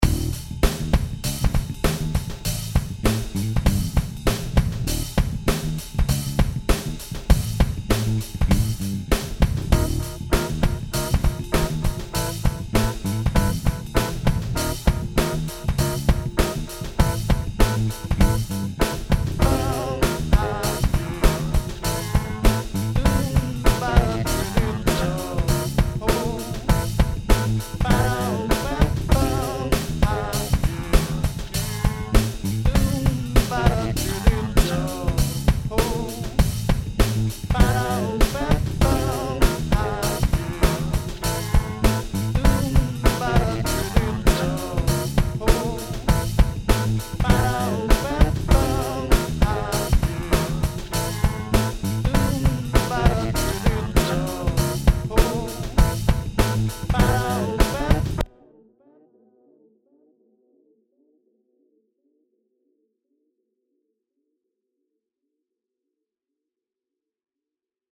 home of the daily improvised booty and machines -
3 bass grooves